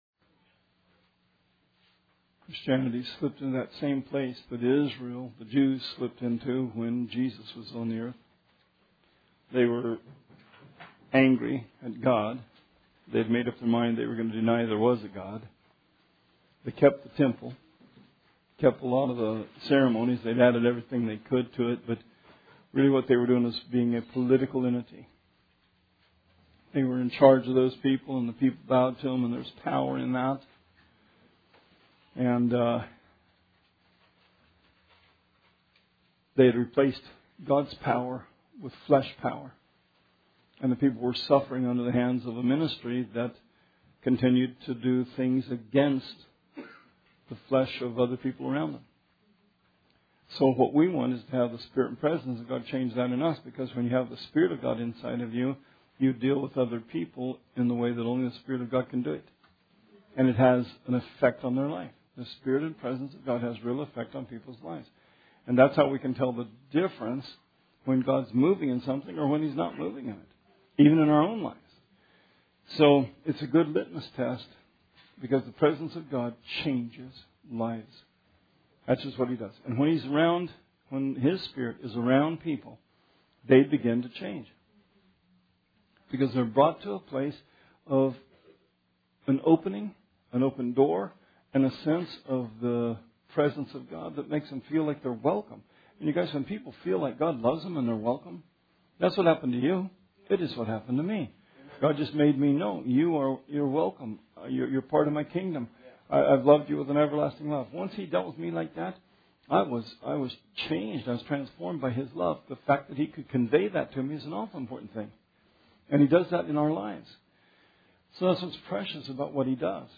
Bible Study 11/8/17